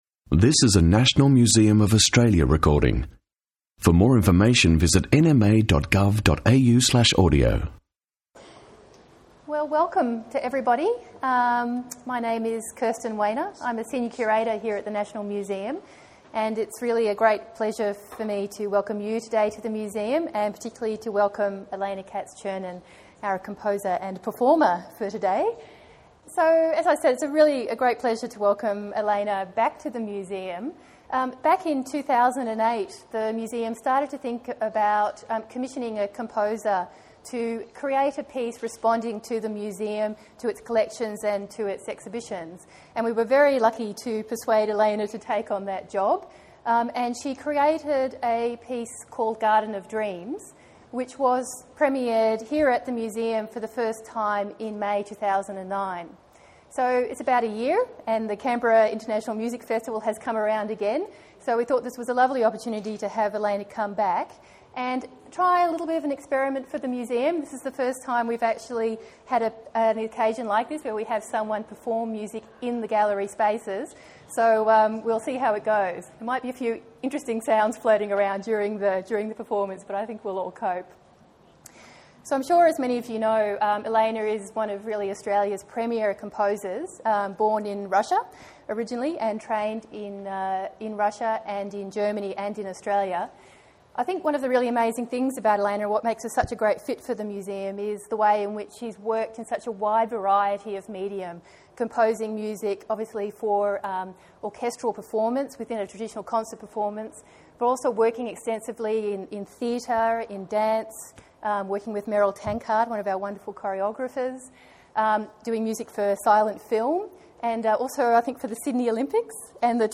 performed in the Australian Journeys gallery